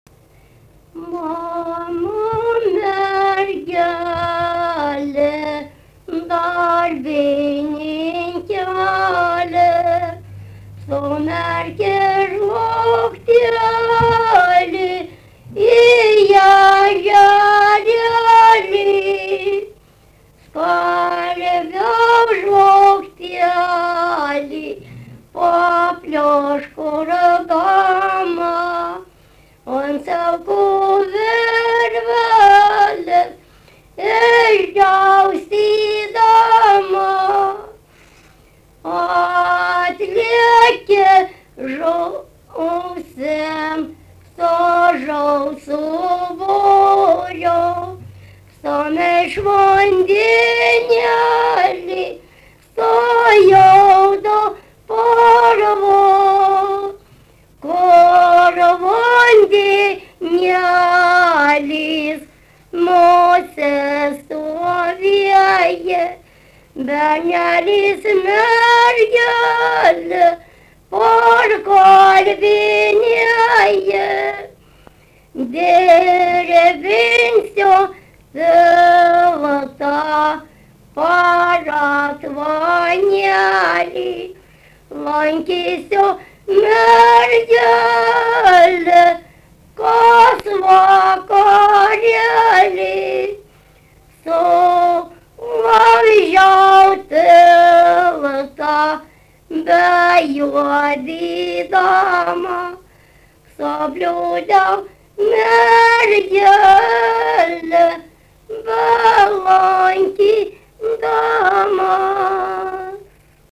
daina
Daukšiai (Skuodas)
vokalinis